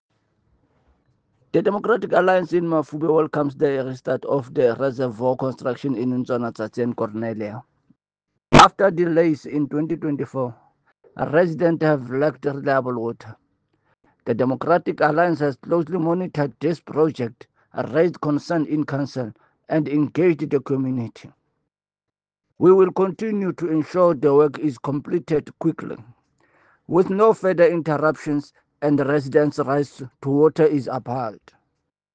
Issued by Cllr. Fako Tsotetsi – DA Councillor Mafube Municipality
Sesotho soundbites by Cllr Fako Tsotetsi.